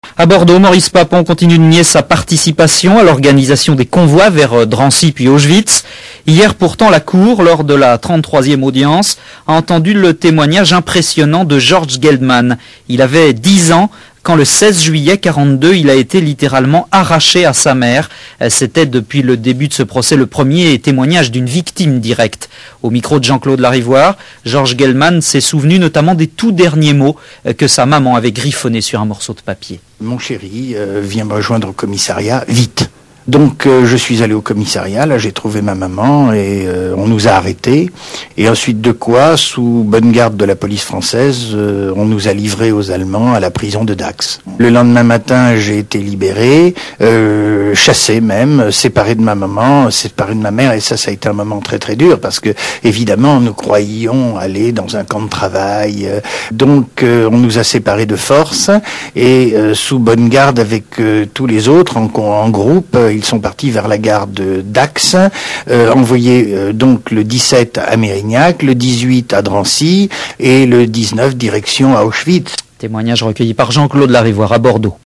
Les infos (radio)